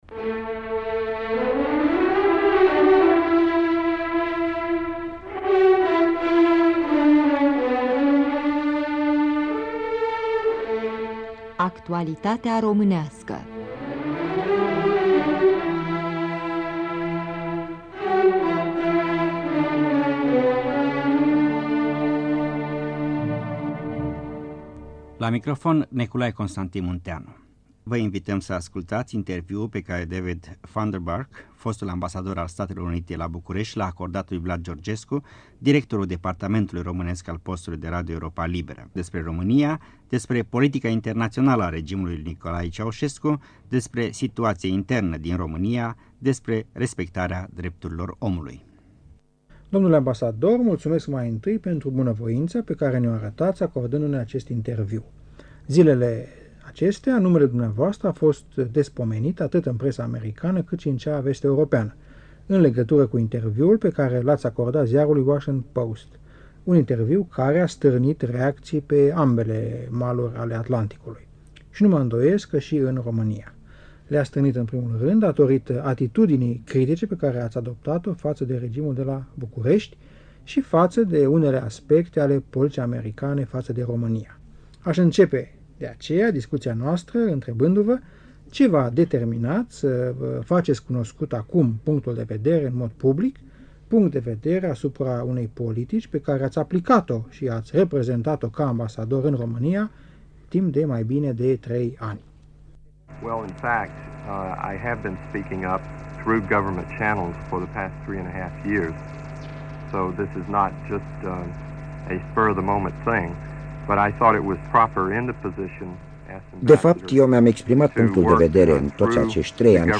Un interviu cu diplomatul american David Funderburk